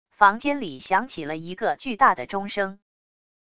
房间里响起了一个巨大的钟声wav音频